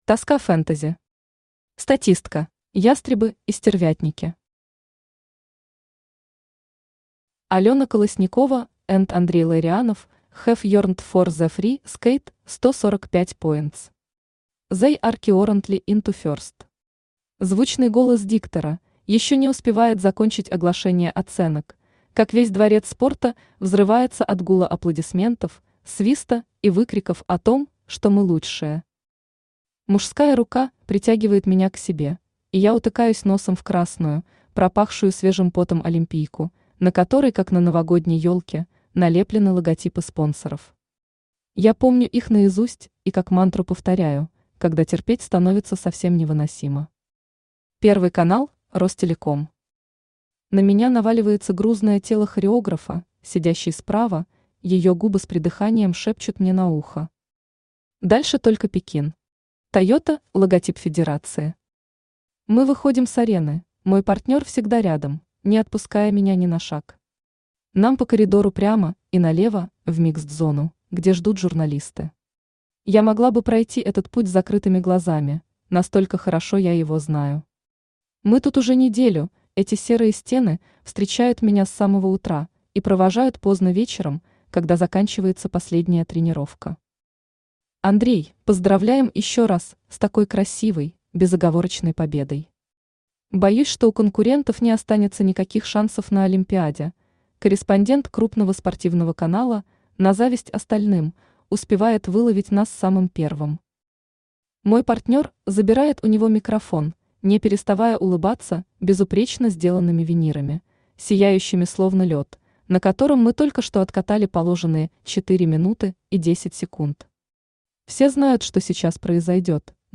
Аудиокнига Статистка | Библиотека аудиокниг
Aудиокнига Статистка Автор Тоска Фэнтези Читает аудиокнигу Авточтец ЛитРес.